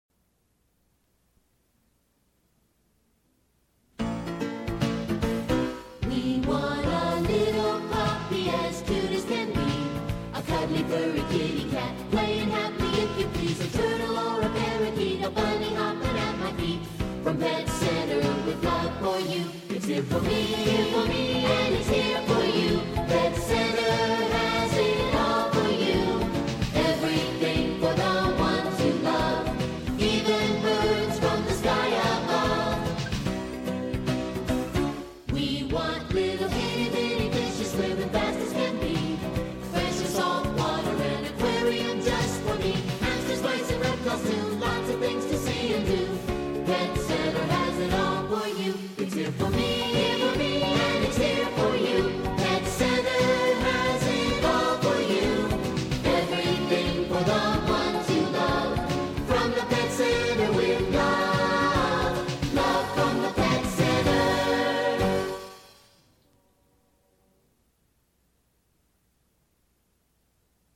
Award Winning Musical themed Commercials / Jingles